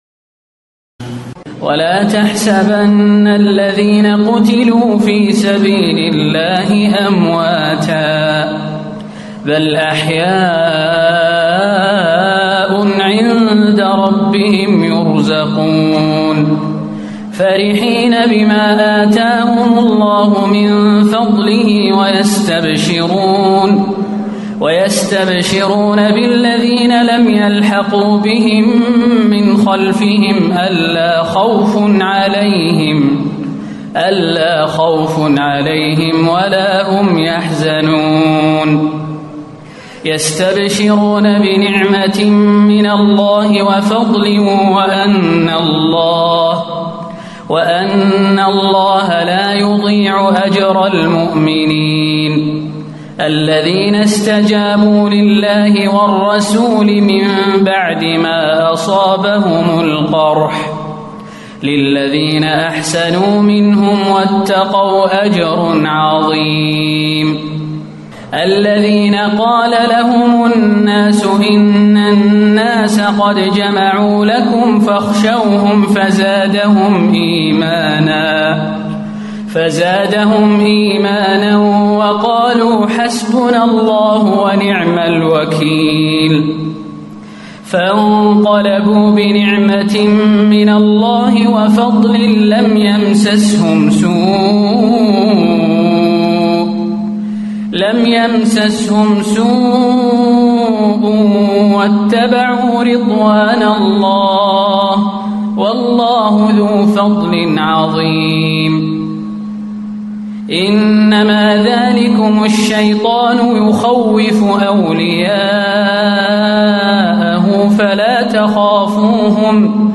تراويح الليلة الرابعة رمضان 1437هـ من سورتي آل عمران (169-200) و النساء (1-24) Taraweeh 4 st night Ramadan 1437H from Surah Aal-i-Imraan and An-Nisaa > تراويح الحرم النبوي عام 1437 🕌 > التراويح - تلاوات الحرمين